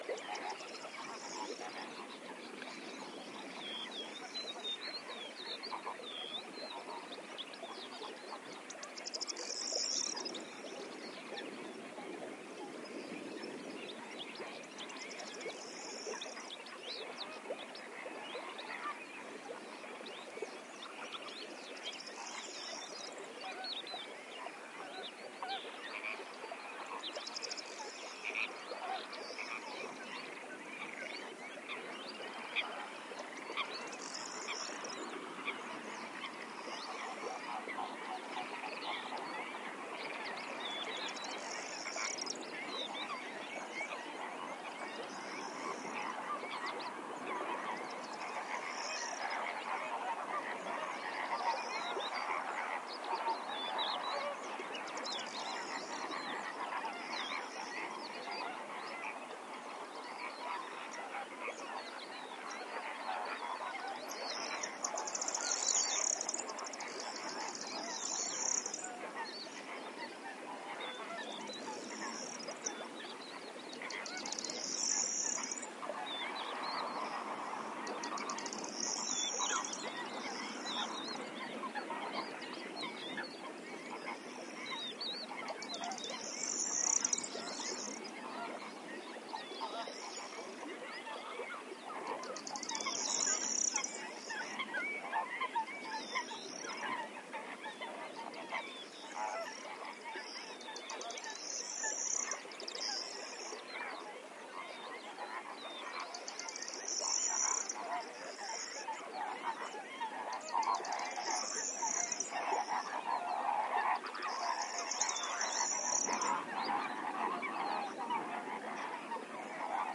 描述：早晨在多纳纳沼泽地的一座建筑物附近的氛围，主要是椋鸟的叫声。
麦克风是Sennheiser ME62，安装在K6系统上。
标签： 氛围 道纳拿 F ield记录 上午 性质 南西班牙 八哥
声道立体声